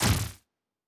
pgs/Assets/Audio/Custom/Combat/Dash.wav at master
Dash.wav